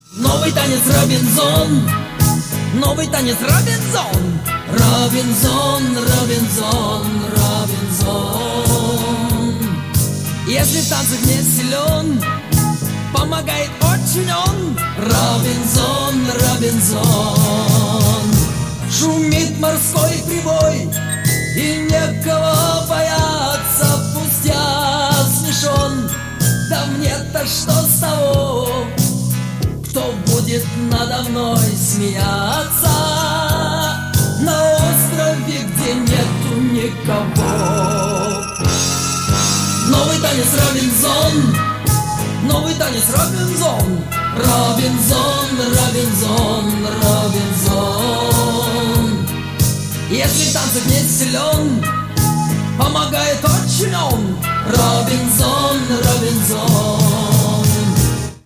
• Качество: 320, Stereo
ретро